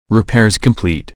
repaircomplete.ogg